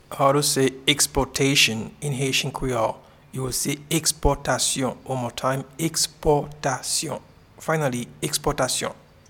Pronunciation and Transcript:
Exportation-in-Haitian-Creole-Ekspotasyon.mp3